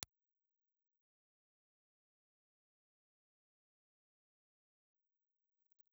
Ribbon
IR file of a Grampian GR ribbon microphone.
Grampian_GRx_IR.wav